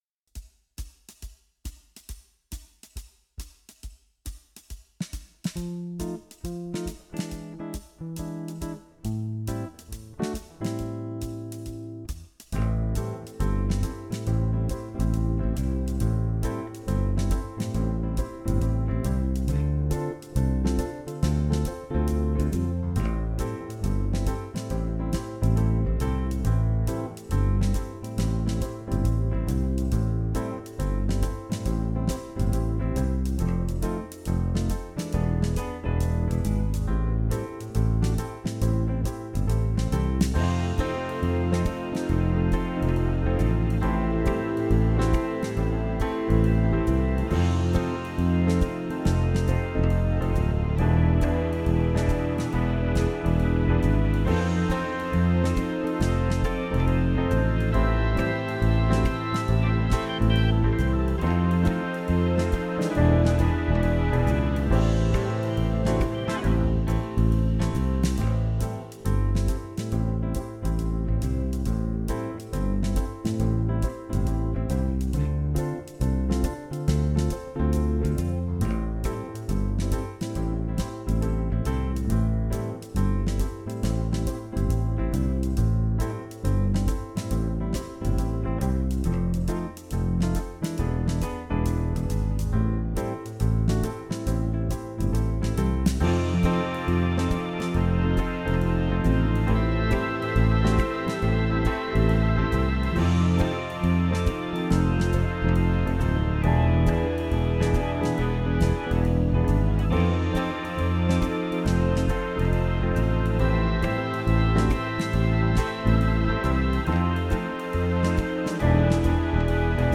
Jam Track